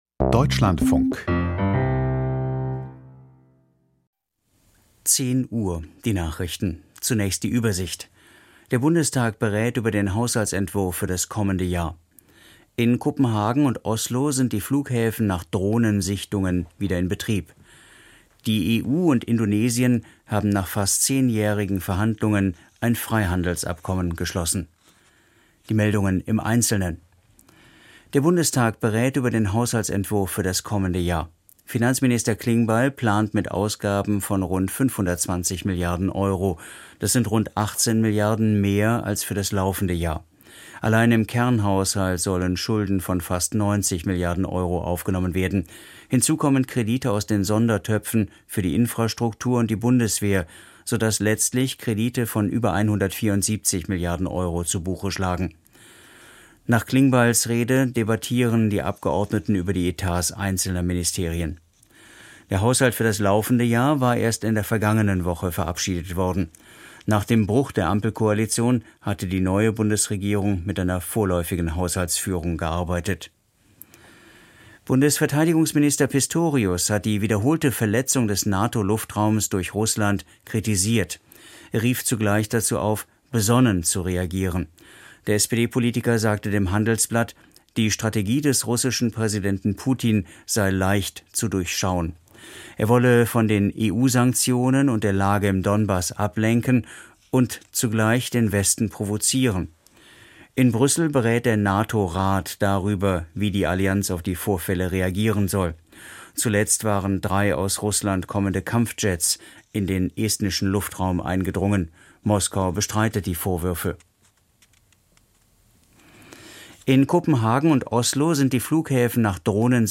Die Nachrichten vom 23.09.2025, 10:00 Uhr